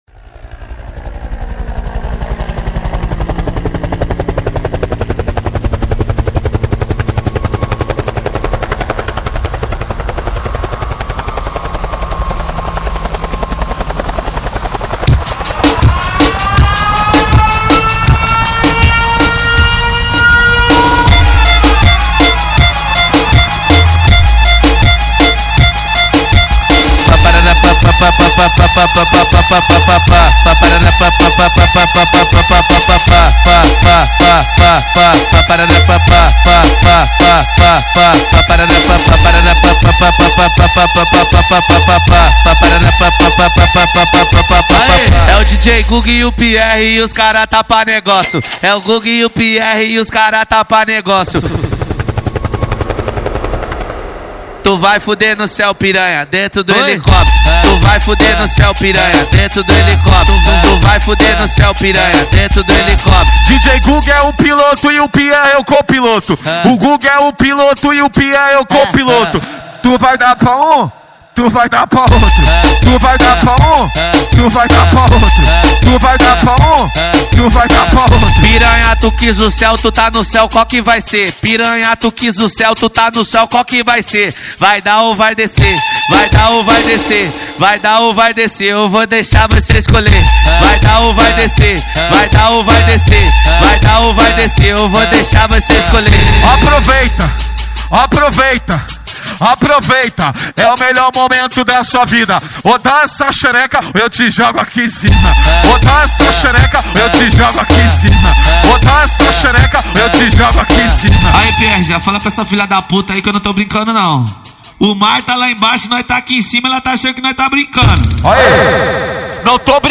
2024-12-25 23:48:30 Gênero: MPB Views